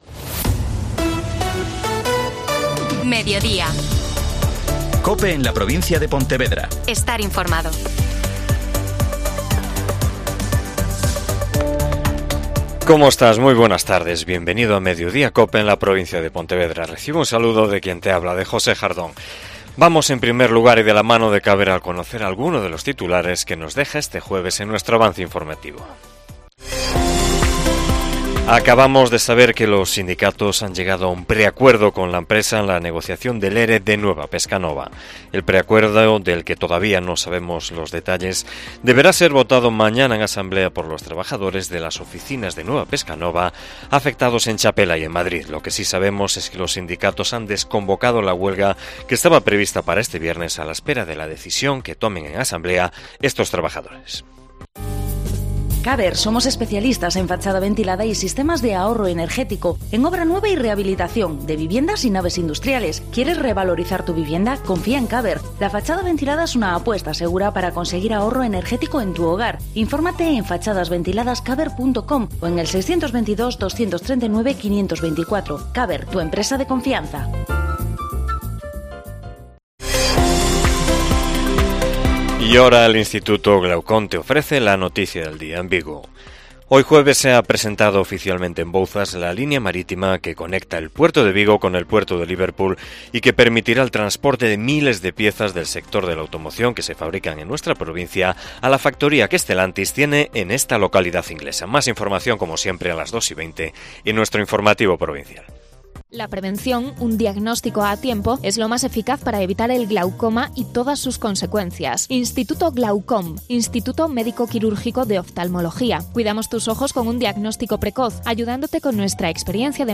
AUDIO: Magazine provincial